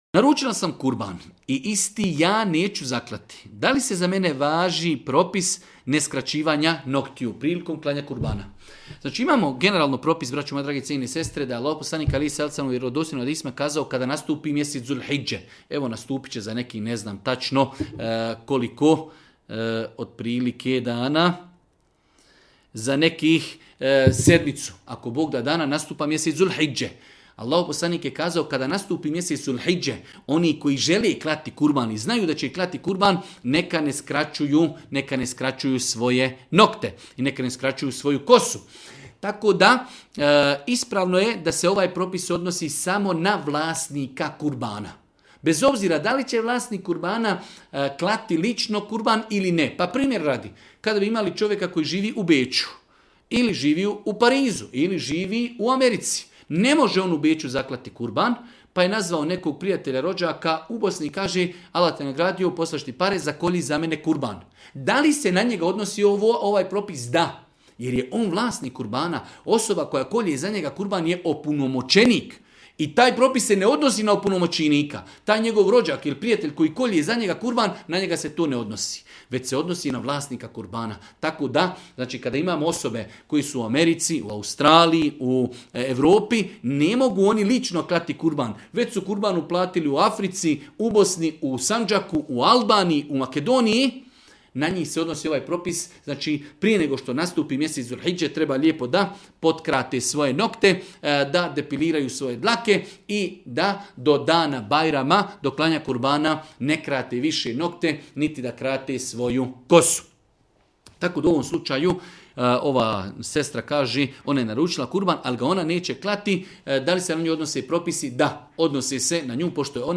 u video predavanju.